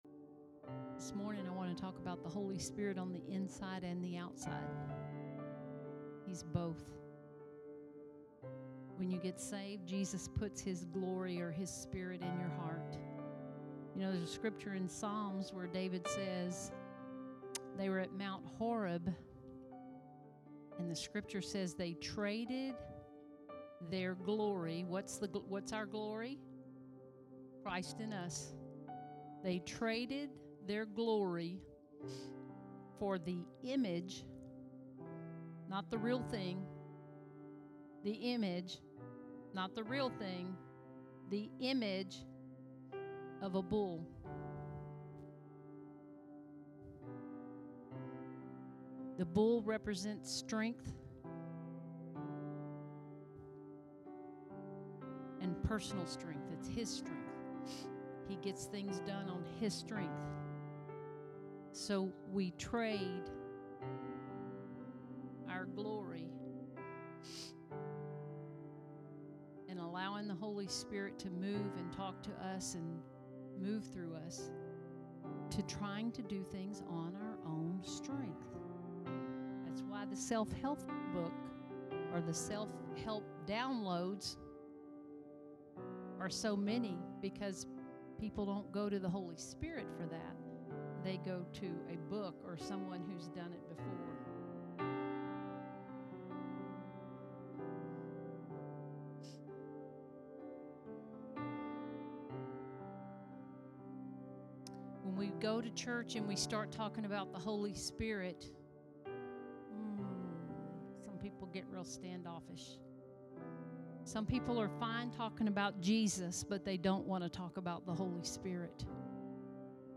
Sermons | Harvest Time Church